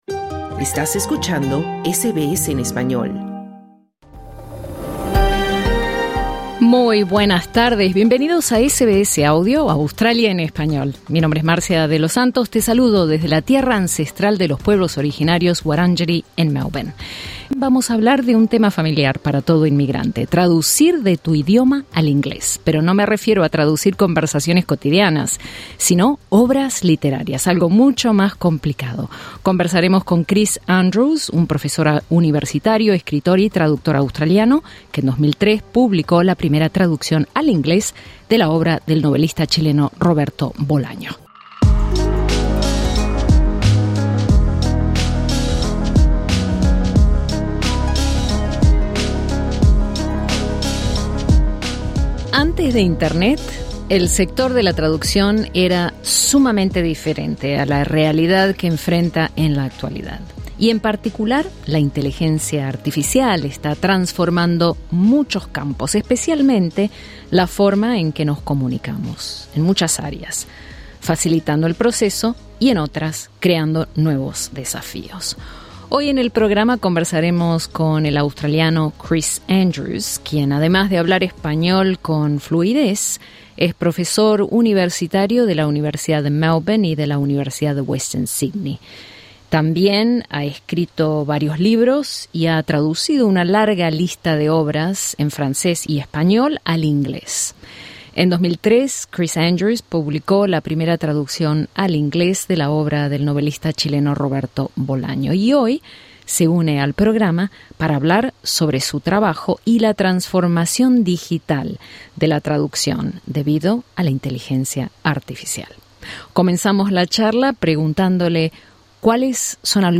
En esta conversación exploramos cómo los traductores humanos afrontan la competencia de la poderosa Inteligencia Artificial (IA) y el futuro que les espera.